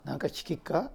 Aizu Dialect Database
Type: Yes/no question
Final intonation: Rising
Location: Showamura/昭和村
Sex: Male